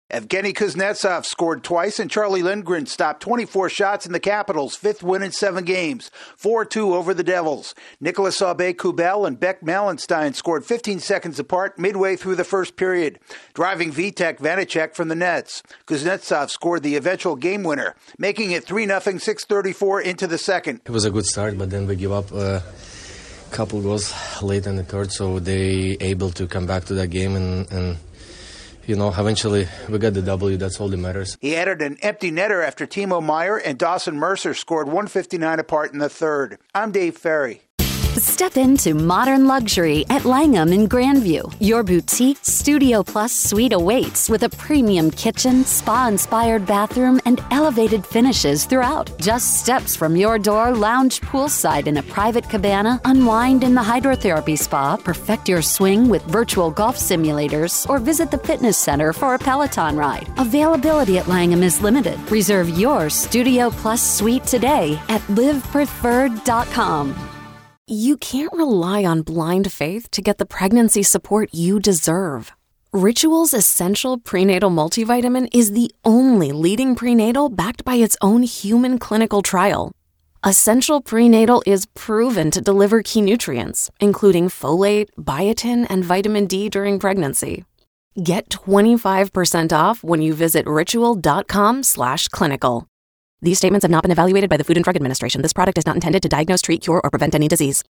The Capitals build a three-goal lead before holding off the Devils. AP correspondent